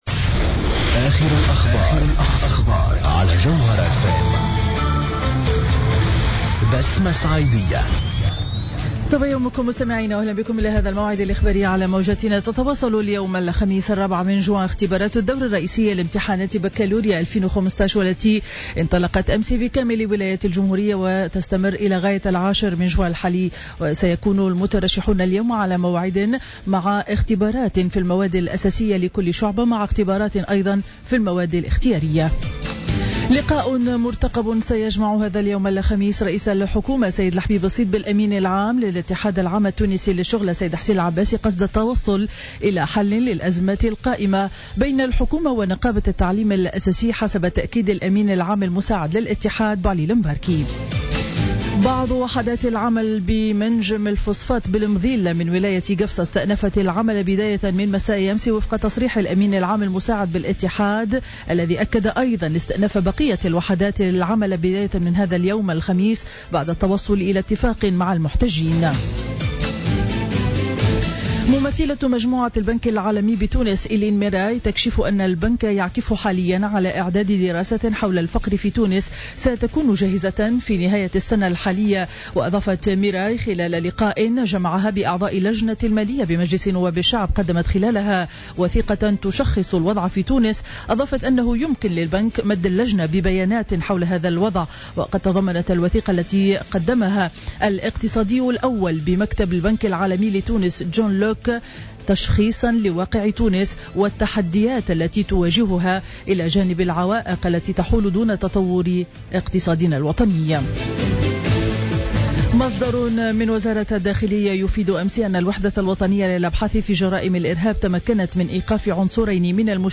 نشرة أخبار السابعة صباحا ليوم الخميس 04 جوان 2015